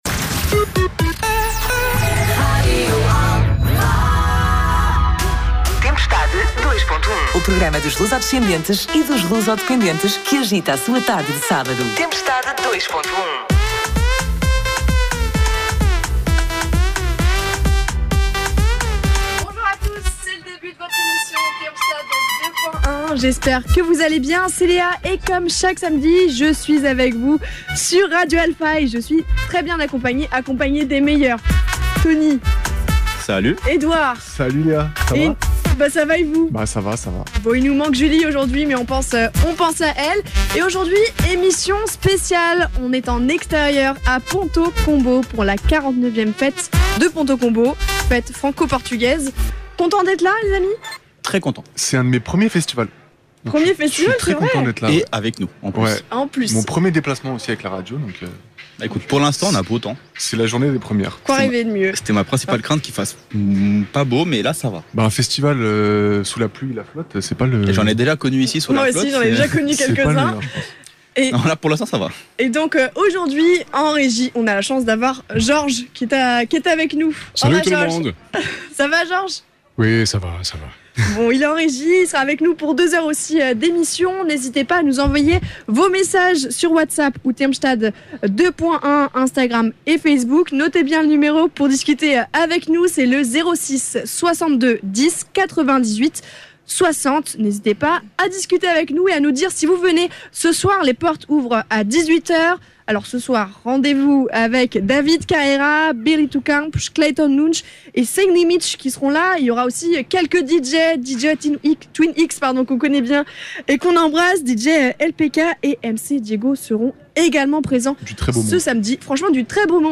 TEMPESTADE 2.1 EM PONTAULT-COMBAULT – 18 DE MAIO 2024